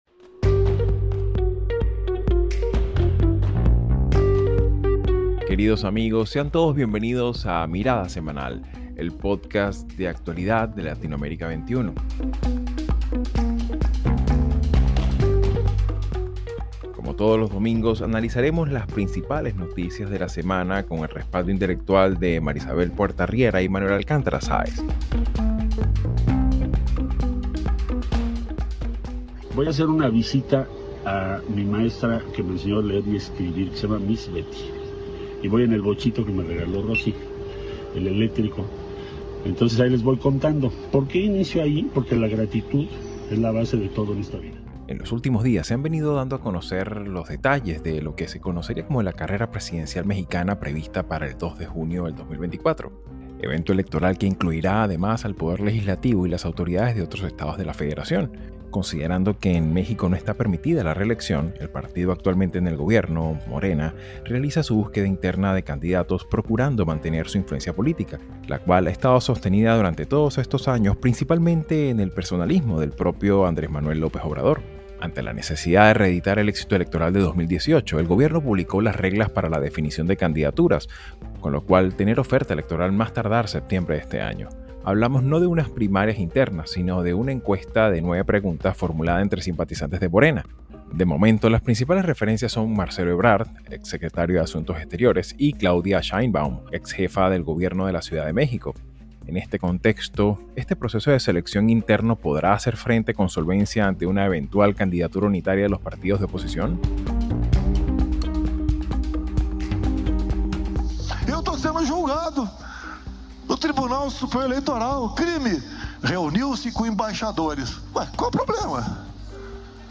Analistas